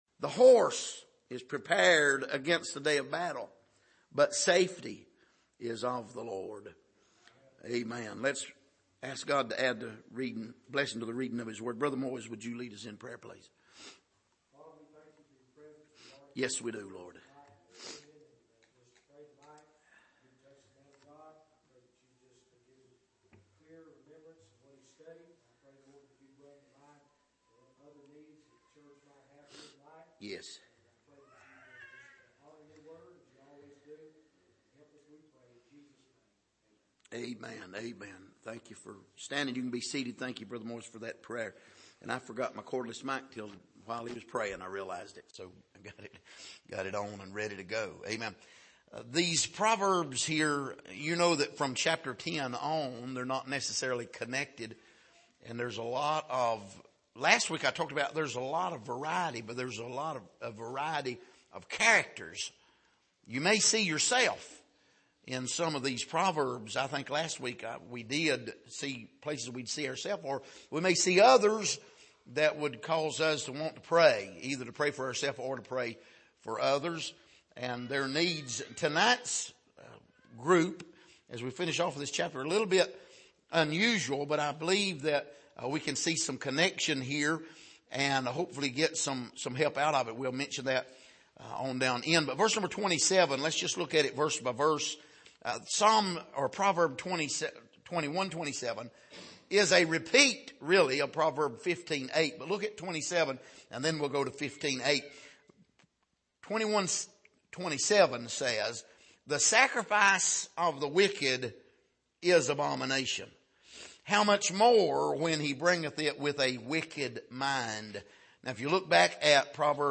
Passage: Proverbs 21:27-31 Service: Sunday Evening